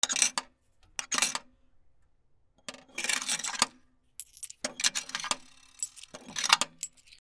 / G｜音を出すもの / G-01 機器_電話
公衆電話 返却口の硬貨を取る
ッジャリン